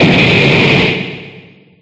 Explosion2.ogg